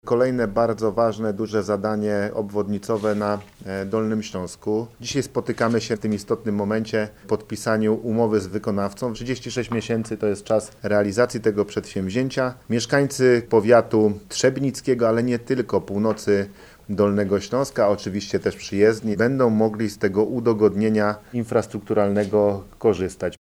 – To bardzo ważne zadanie, wyczekiwane latami – mówi Paweł Gancarz, marszałek Województwa Dolnośląskiego.